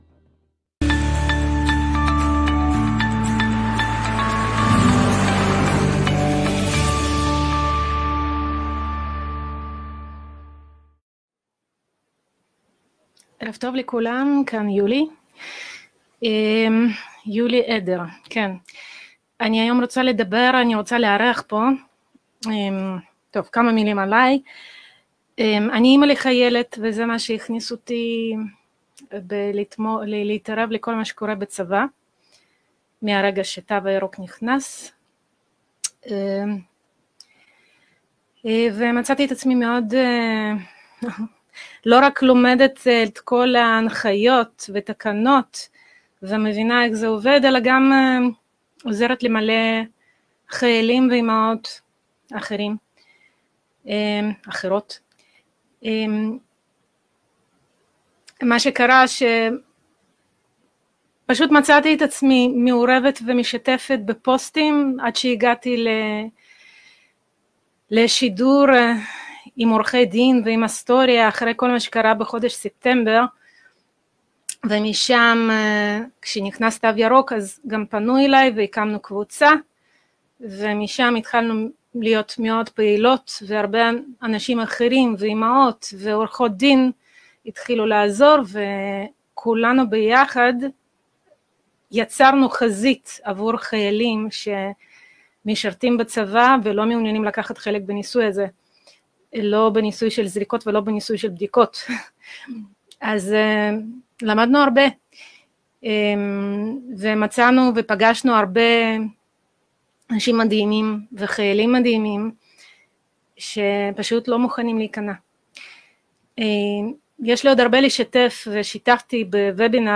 ראיון